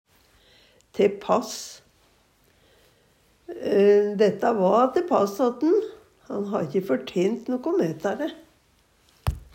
te pass - Numedalsmål (en-US)